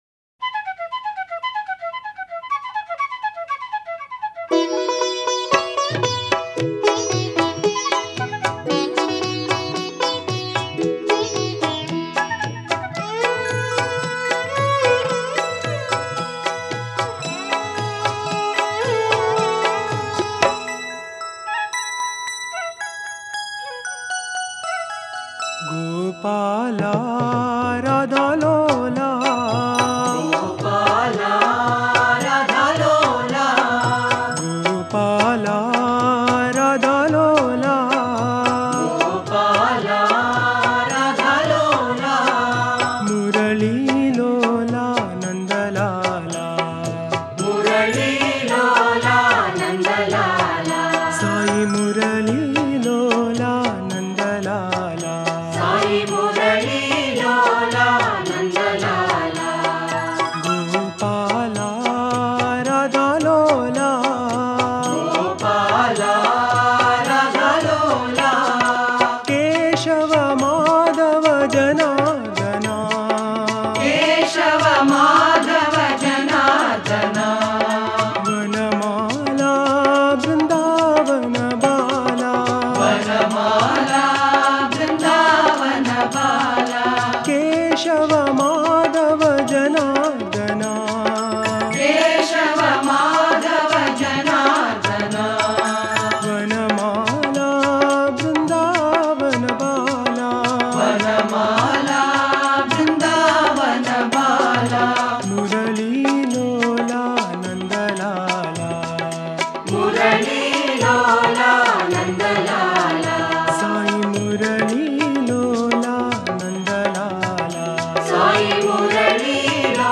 Author adminPosted on Categories Krishna Bhajans